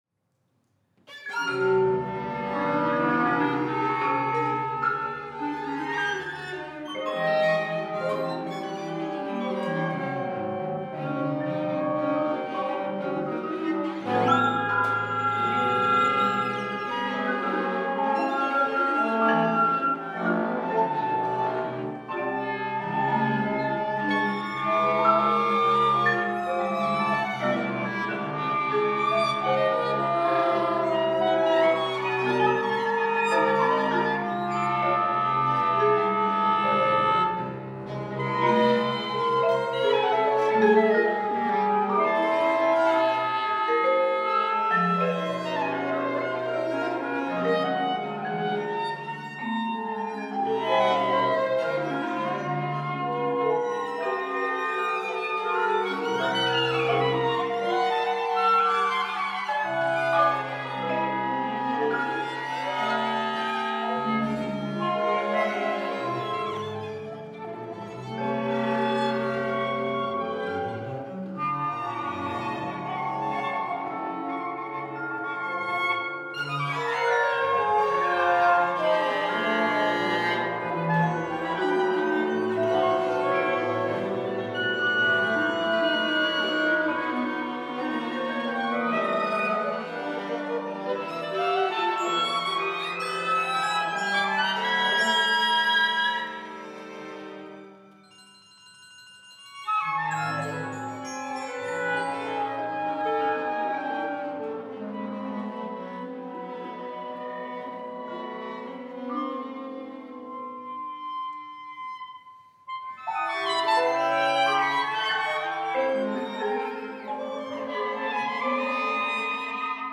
PALM for octet
These moving visual images are resembled in the terms of sound spectra, which allow actualizing a temporal sonic world in panoramic sound landscape is heard as moving texture and timbre in twenty one groups under ten large shifts. To succeed in an approach of the listening perception, the groups of movements by frequency modulations in interlacing texture are shaped selectively through the observation of the 24-hour cycle live cam of the northern lights.